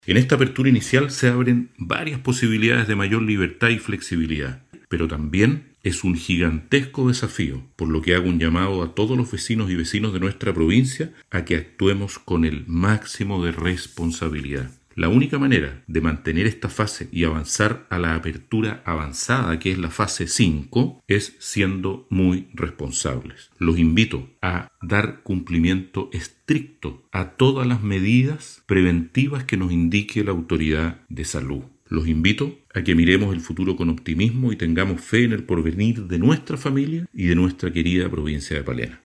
El Gobernador de Palena José Luis Carrasco, dijo que el estar en fase 4 del plan Paso a Paso, es producto del buen comportamiento de autocuidado que han adoptado los habitantes de la zona, sin embargo invitó a toda la comunidad a continuar adoptando medidas de seguridad sanitaria a objeto de lograr pasar pronto a fase 5.